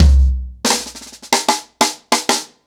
Wireless-90BPM.5.wav